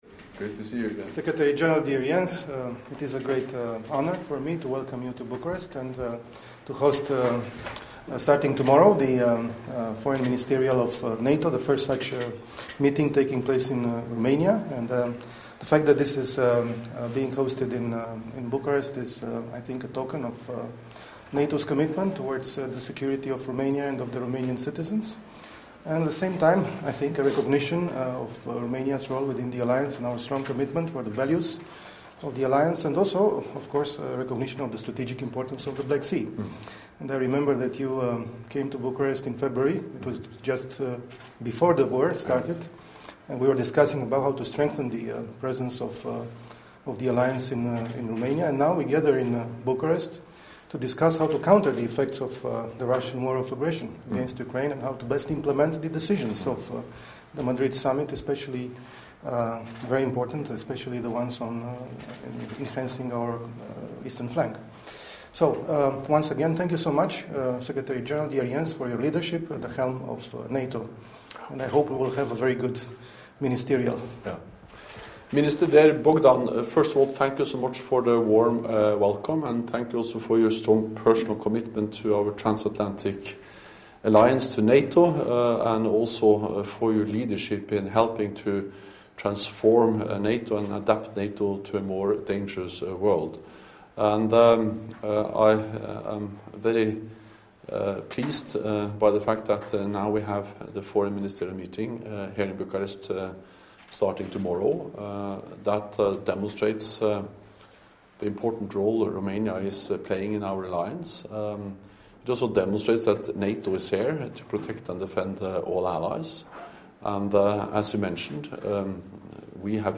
Short remarks
by NATO Secretary General Jens Stoltenberg and the Minister of Foreign Affairs of Romania Bogdan Aurescu